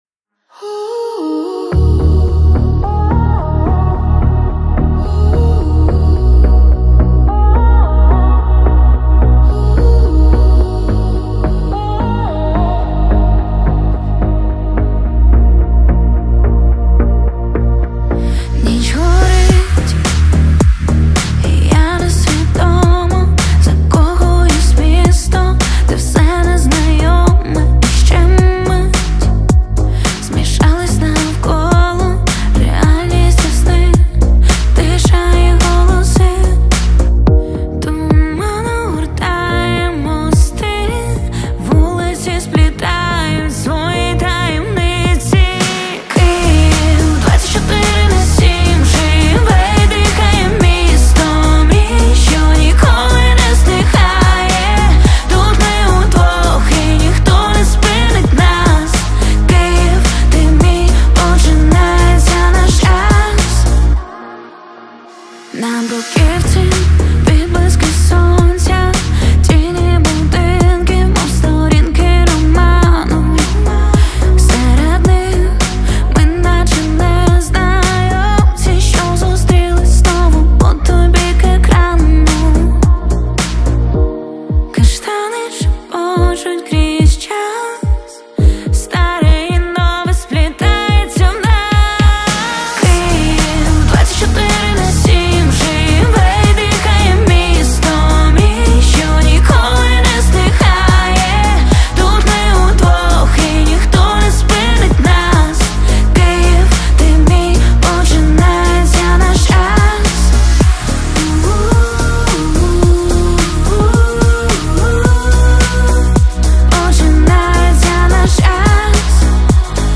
• Жанр:Поп